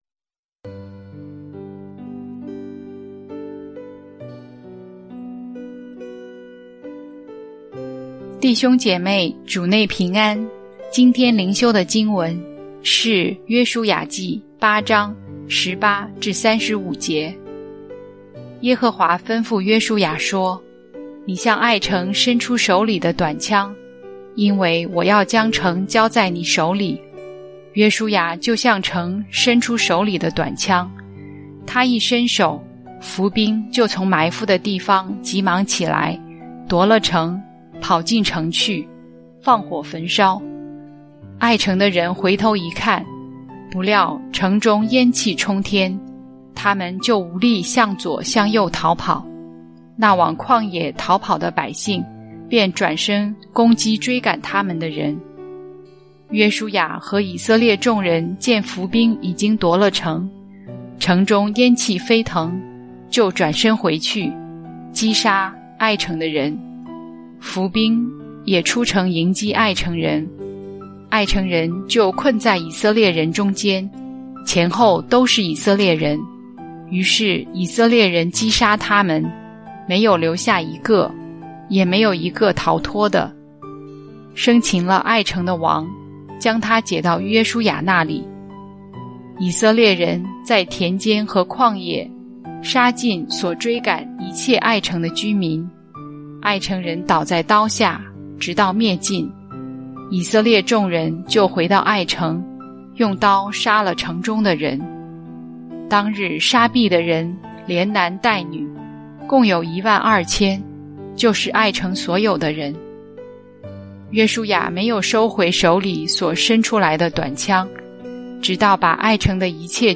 牧長同工分享：艾城的得勝